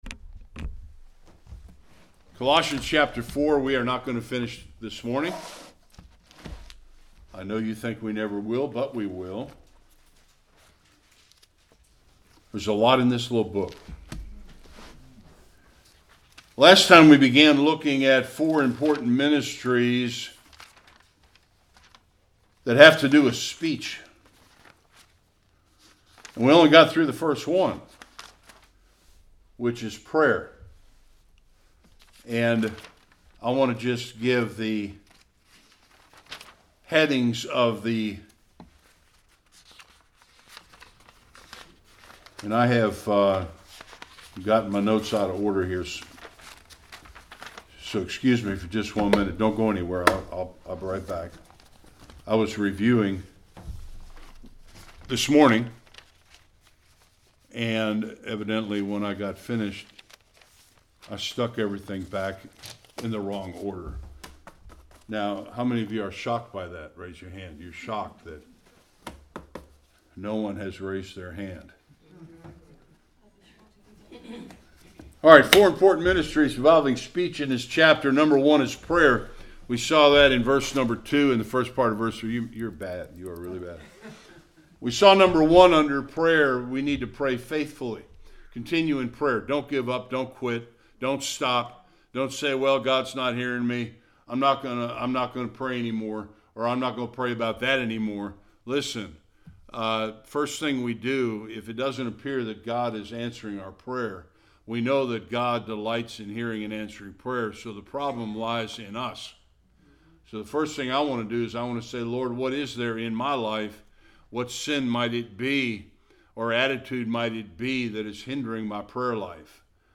3b-9 Service Type: Sunday Worship Last 3 of 4 ministries that come out of God’s gift of speech.